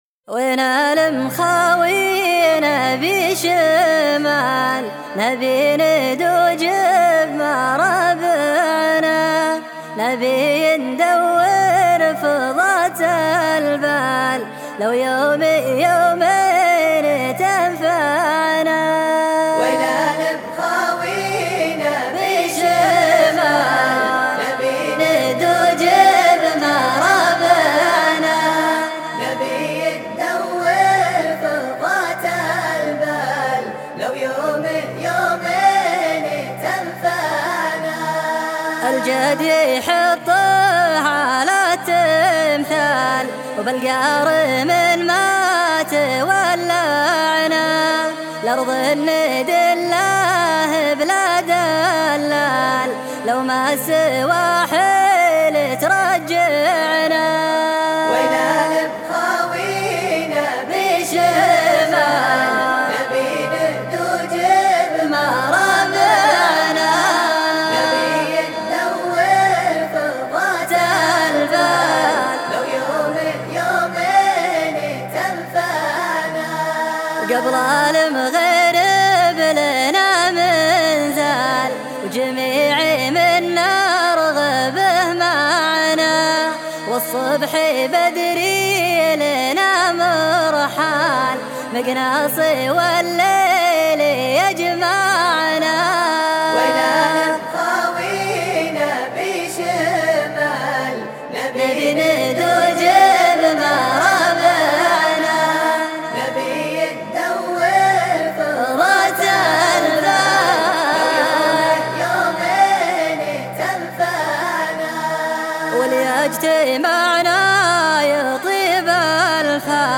الشيله